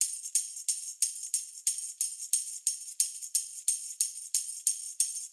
Index of /musicradar/sampled-funk-soul-samples/90bpm/Beats
SSF_TambProc2_90-03.wav